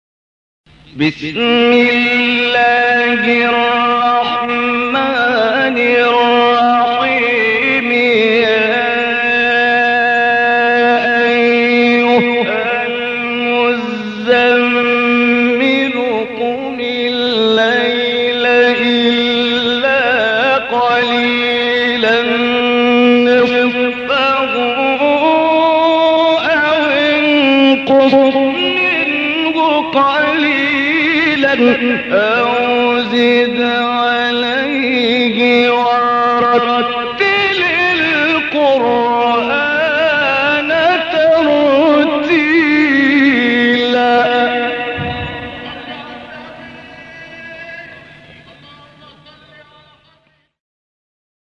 گروه شبکه اجتماعی: 10 مقطع صوتی از قاریان برجسته مصری که در مقام رست اجرا شده‌ است، می‌شنوید.
مقام رست